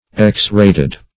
Definition of x-rated. What does x-rated mean? Meaning of x-rated. x-rated synonyms, pronunciation, spelling and more from Free Dictionary.